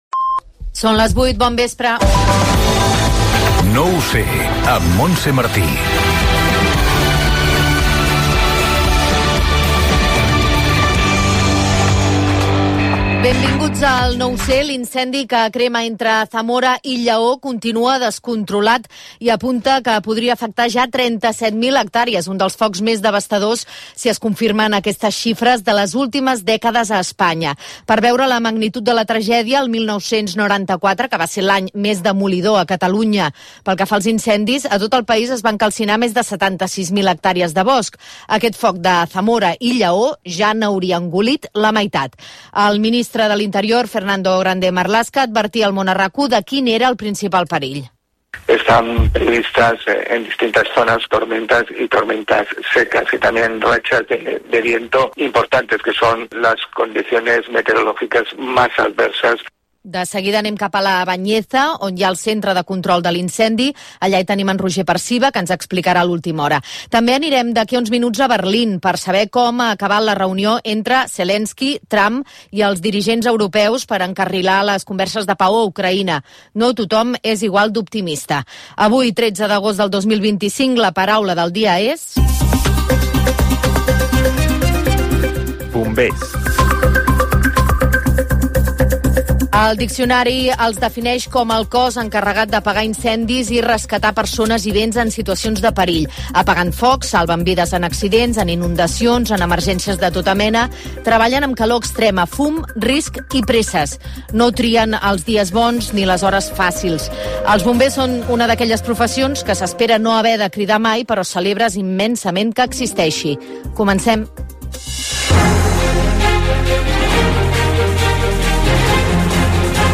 Hora, careta, resum informatiu, la paraula del dia "bomber", informació dels incendis forestals a l'Estat espanyol (declaració del ministre Grande Marlasca),connexió amb l'incendi entre Lleó i Zamora , la resta d'incendis a l'Estat espanyol, l'onada de calor i la previsió del temps, mor un temporer per un cop de calor a Alcarràs Gènere radiofònic Informatiu